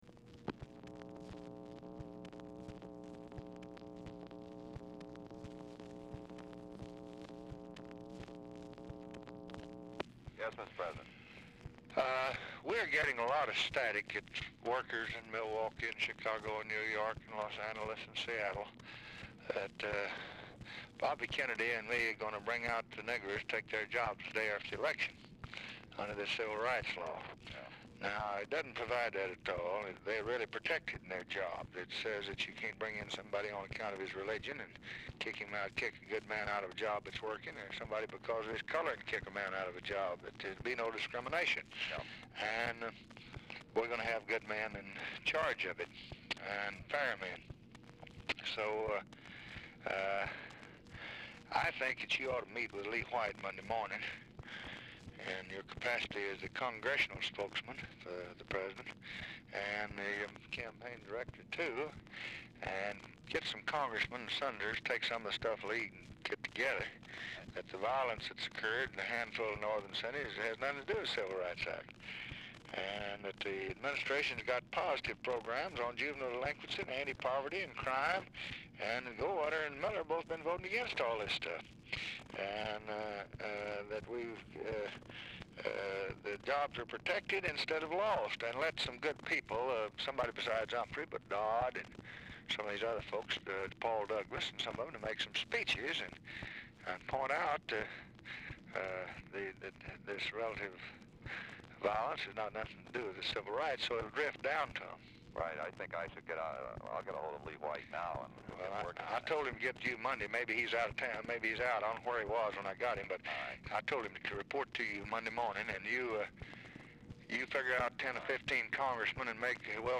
Telephone conversation # 5620, sound recording, LBJ and LARRY O'BRIEN, 9/19/1964, 5:52PM | Discover LBJ
Format Dictation belt
Location Of Speaker 1 Oval Office or unknown location
Specific Item Type Telephone conversation